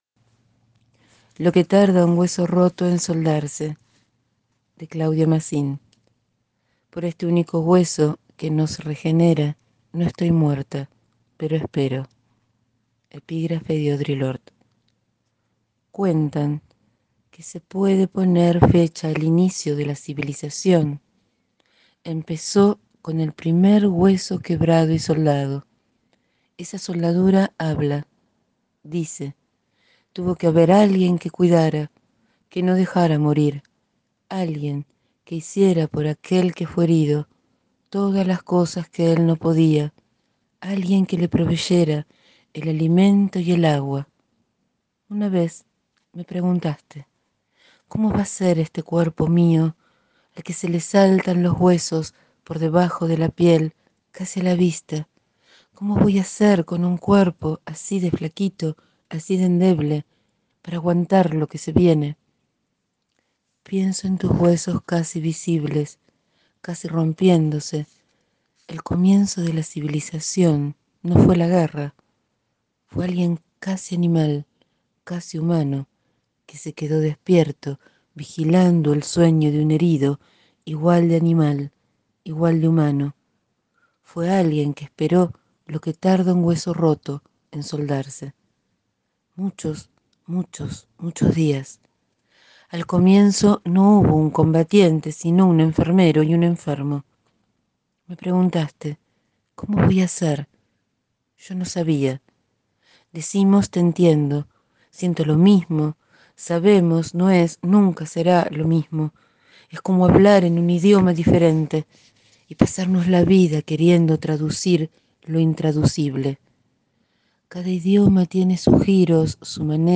Hoy leo un poema de Claudia Masin (Chaco – Argentina 1972)